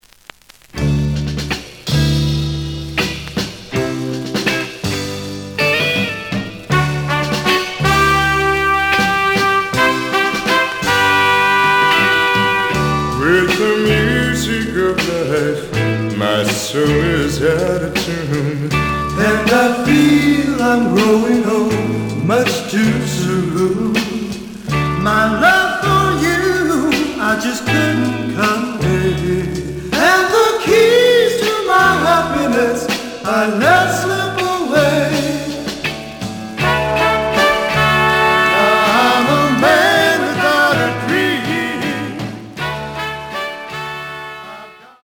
The audio sample is recorded from the actual item.
●Genre: Rock / Pop
Slight cloudy on B side.